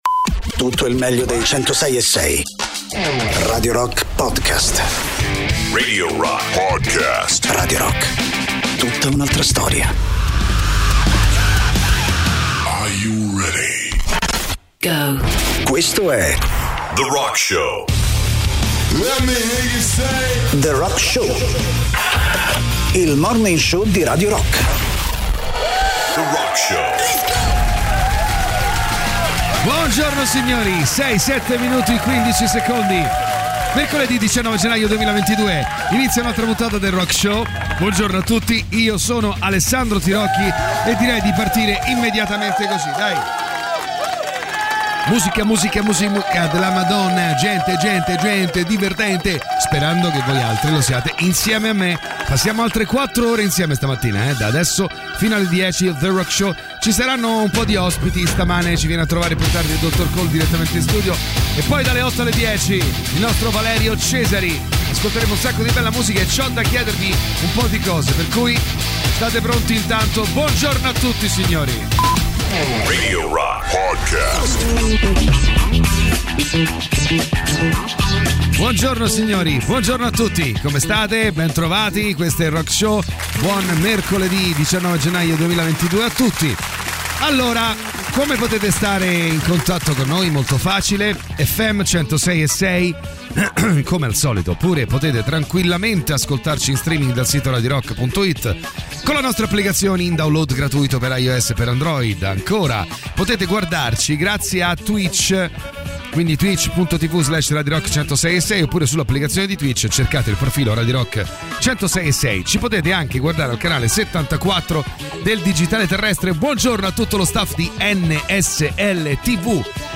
in diretta dal lunedì al venerdì dalle 6 alle 10 sui 106.6 di Radio Rock